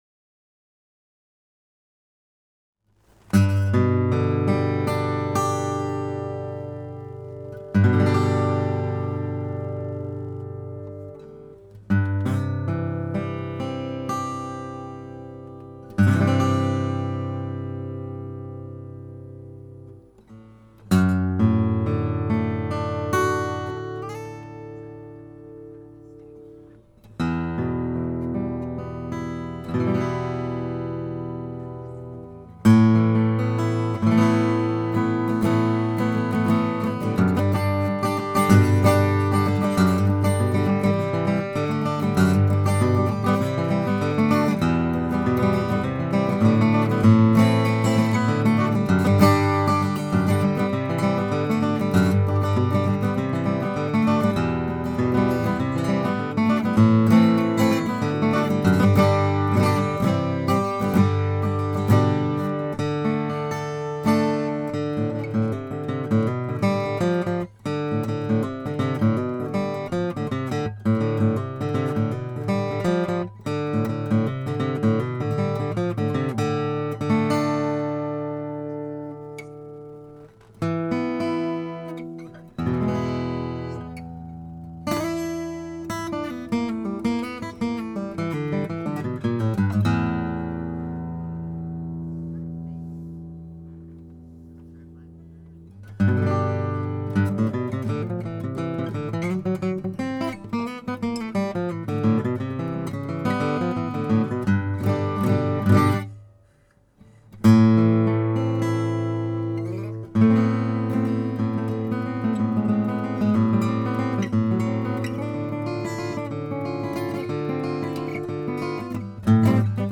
Many of these were made here in the shop about as simply as they could be done.
OM-M No167, the classic guitar everyone loves.